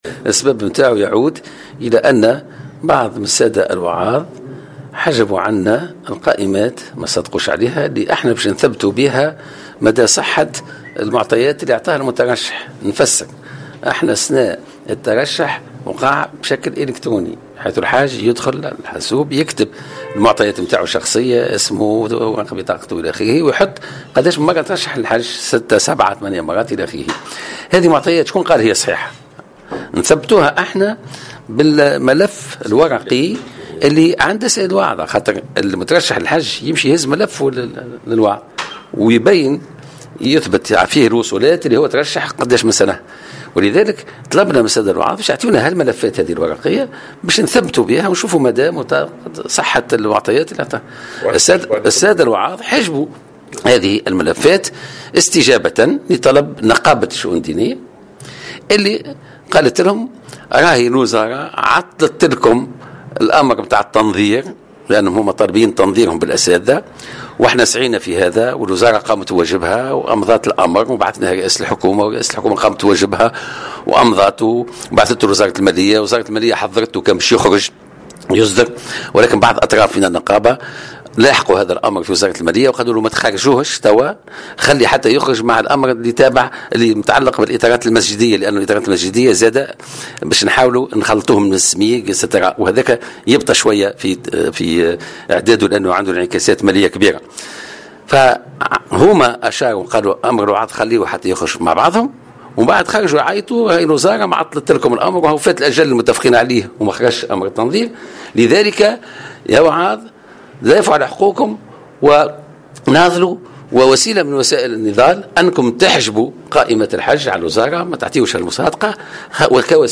وأوضح في تصريحات صحفية اليوم الأحد على هامش إشرافه اليوم الأحد بصفاقس على ندوة جهوية لفائدة الوعاظ والأئمة الخطباء، إن هذه الوضعية تسبب فيها الوعاظ حيث تعمدوا حجب القائمات الاسمية للمترشّحين للحج استجابة لطلب نقابة الشؤون الدينية التي أوهمتهم بأن وزارة الشؤون الدينية تسعى إلى تعطيل ملفاتهم بشأن تنظير وضعياتهم مع أساتذة التعليم الثانوي لتمكينهم من نفس الحقوق والامتيازات.